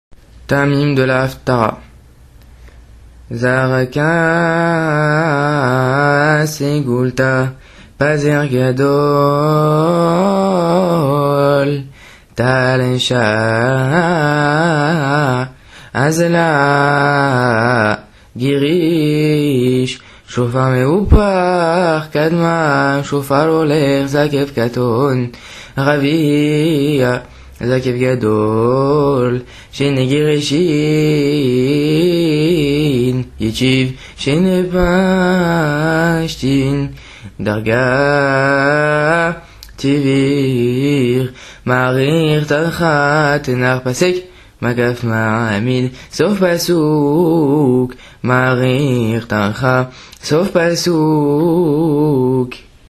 Taamimes de la Haphtara - Synagogue Rebbi Hai Taieb Lo Met